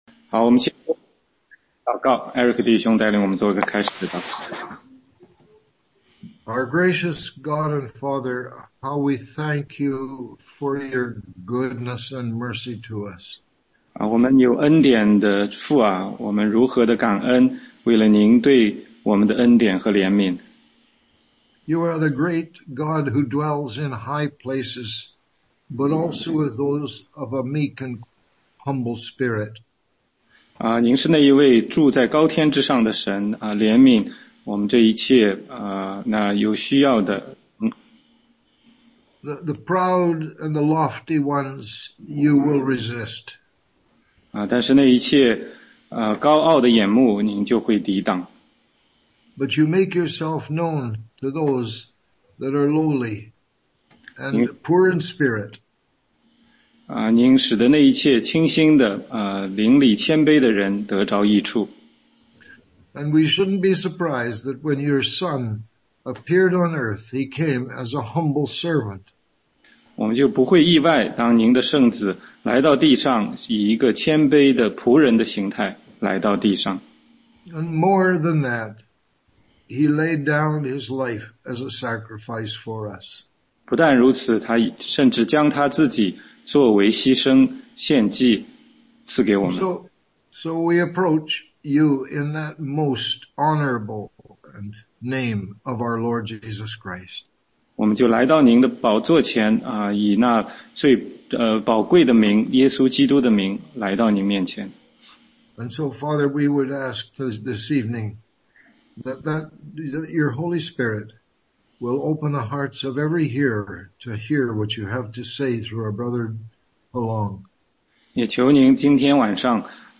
16街讲道录音 - 耶和华你的神默然爱你